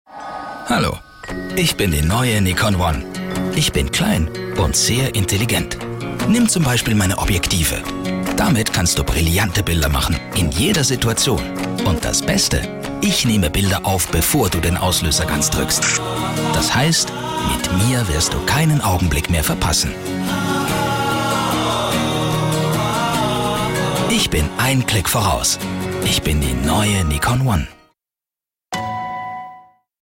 Werbung Hochdeutsch (CH)